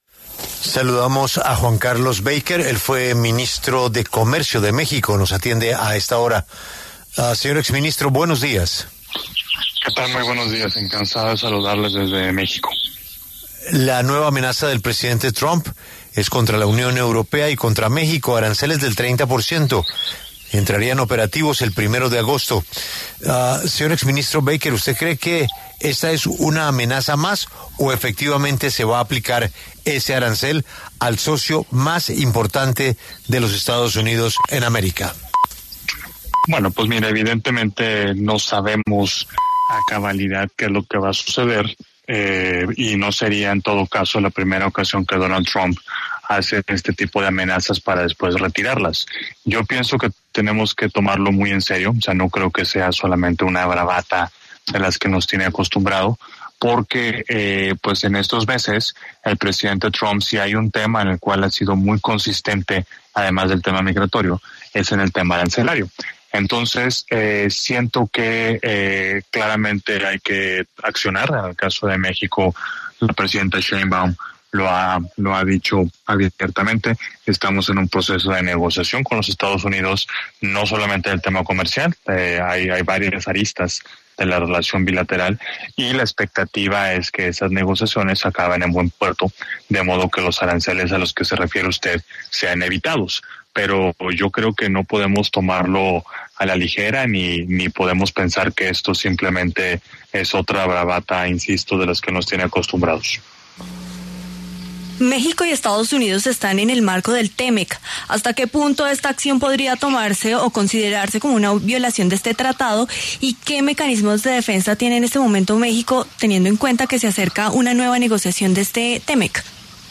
Juan Carlos Baker, ex subsecretario de Comercio Exterior de México, pasó por los micrófonos de La W, con Julio Sánchez Cristo, para conversar en relación al reciente anuncio del presidente estadounidense, Donald Trump, de establecer aranceles del 30% contra México y la Unión Europea a partir del 1 de agosto.